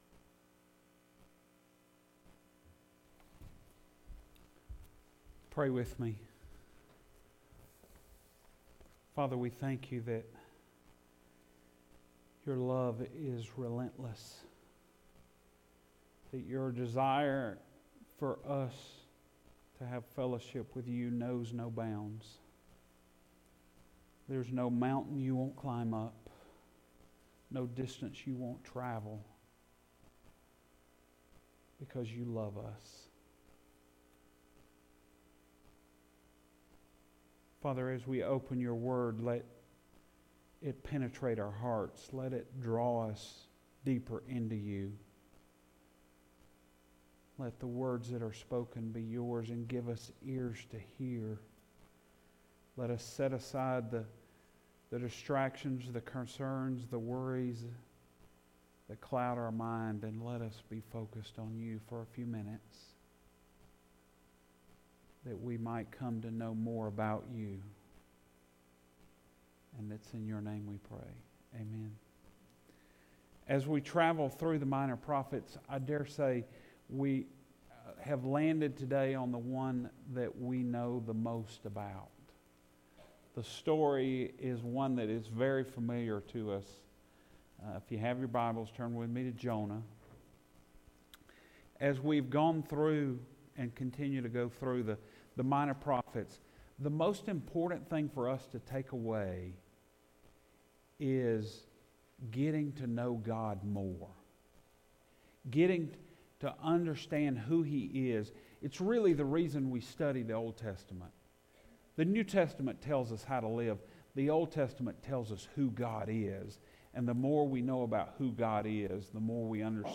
Minor Prophets - Major Lessons Sunday Morning Sermon